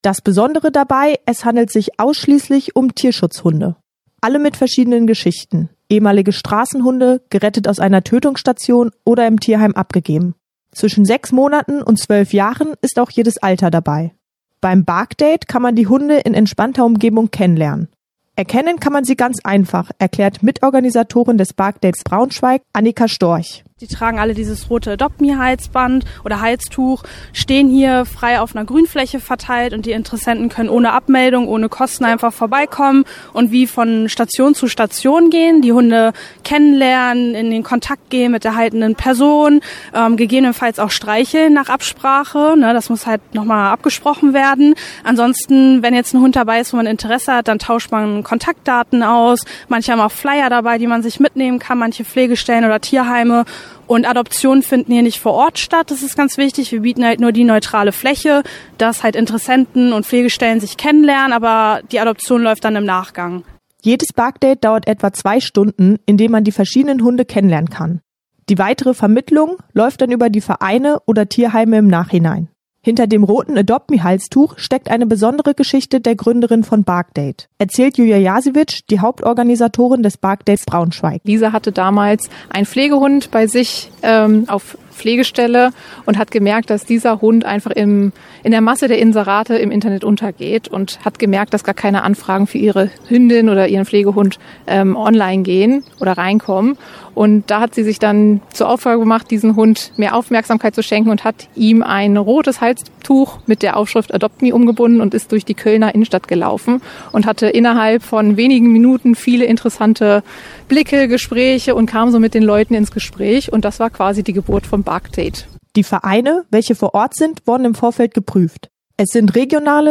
bei einem dieser Schnuppertreffen hautnah erlebt